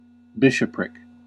Ääntäminen
UK : IPA : /ˈbɪʃ.ə.pɹɪk/